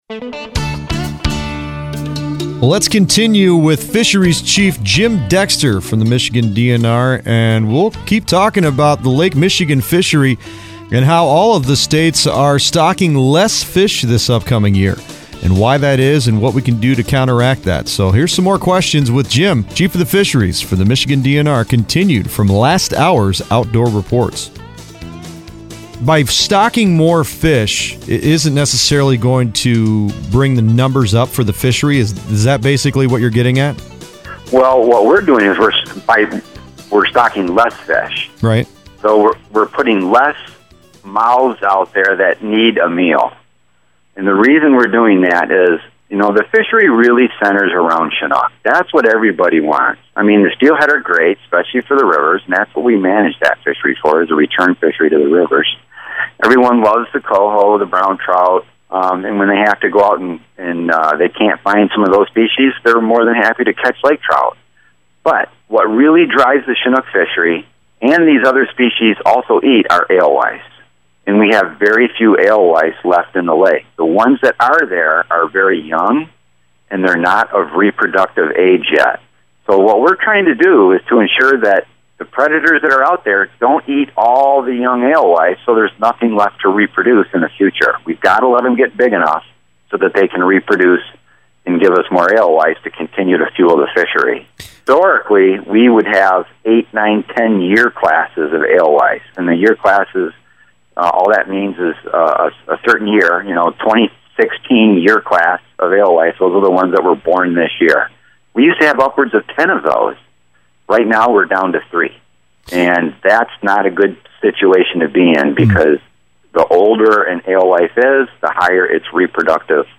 Marquette, MichiganOctober 25, 2016 – We talked with Michigan DNR Fisheries Chief Jim Dexter throughout today’s show.  We talked about declining salmon and trout returns, alewives, and invasive mussels causing this whole problem.